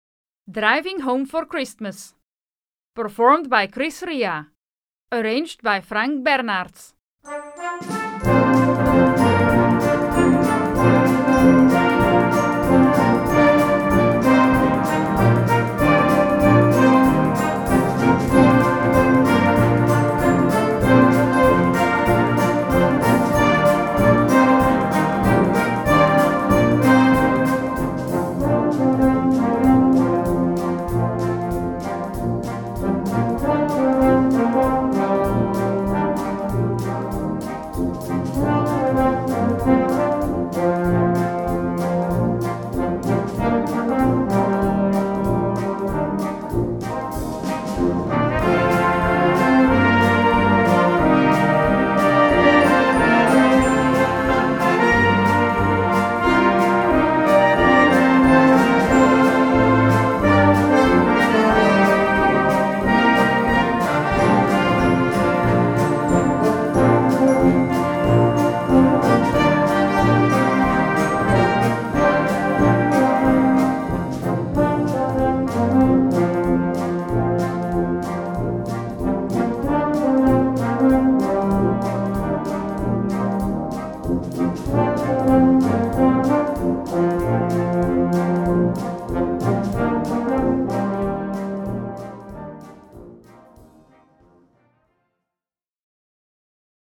Gattung: Weihnachten
Besetzung: Blasorchester